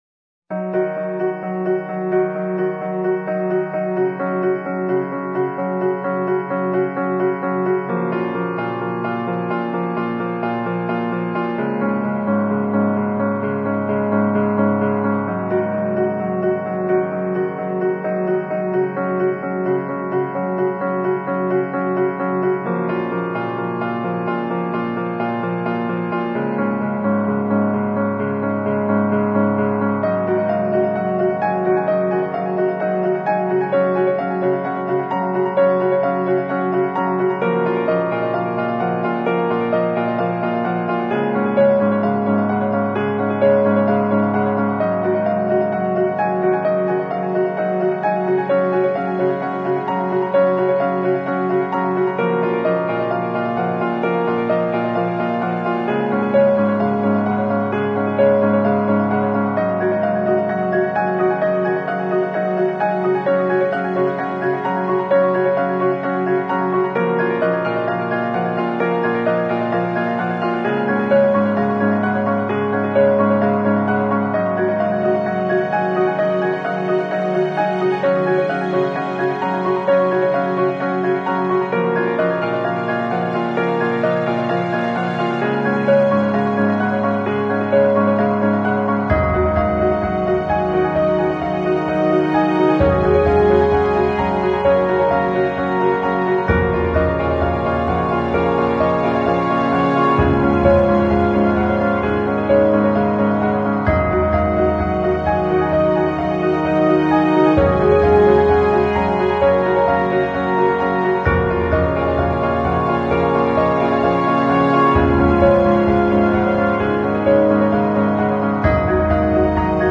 由沉思和情感钢琴组成，强大的铜管乐器，令人敬畏的管弦乐。